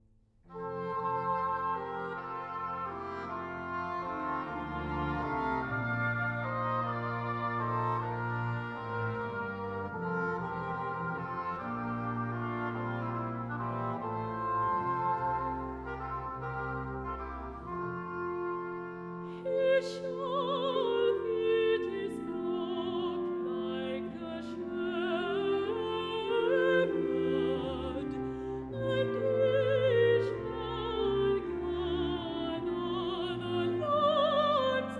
Classical Baroque Era Brass Woodwinds Oratorio
Жанр: Классика